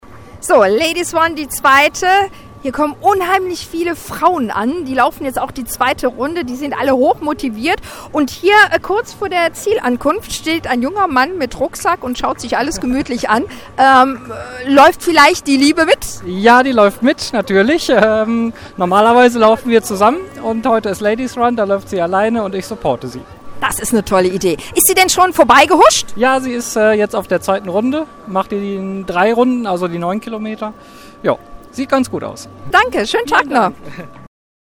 Unter dem Motto BeActive ging gestern die Europäische Woche des Sports an den Start. Auftaktveranstaltung war der Ladies Run in Eupens Innenstatdt.